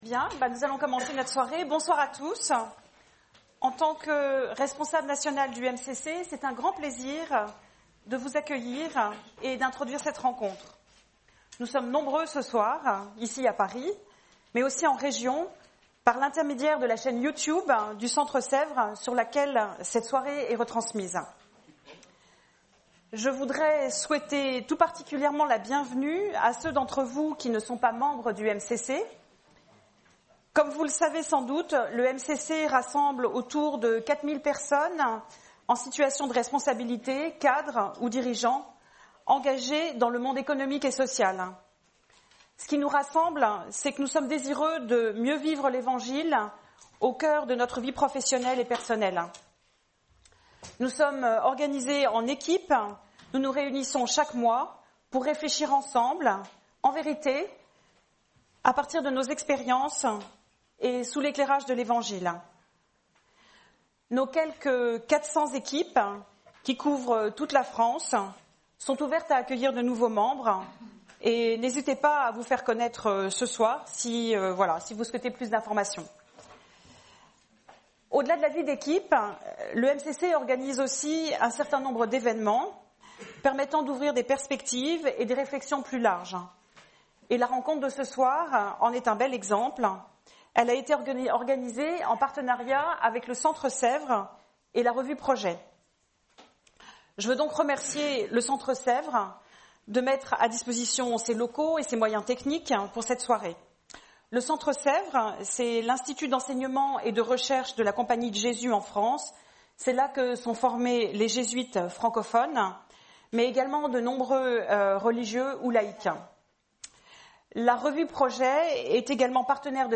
Le sociologue allemand Hartmut Rosa était l'invité du MCC le 27 septembre 2018 pour une conférence sur la Résonance, en partenariat avec la Revue